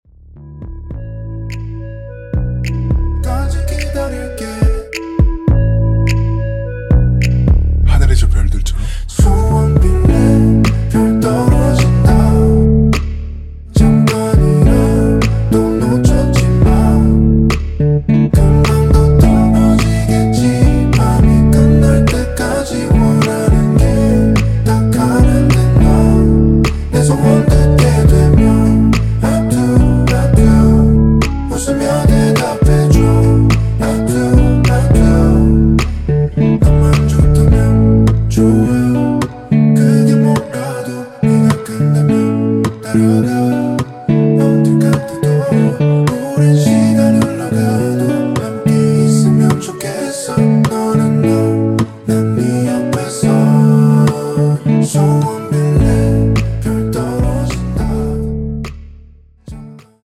원키에서(-2)내린 멜로디와 코러스 포함된 MR입니다.(미리듣기 확인)
◈ 곡명 옆 (-1)은 반음 내림, (+1)은 반음 올림 입니다.
앞부분30초, 뒷부분30초씩 편집해서 올려 드리고 있습니다.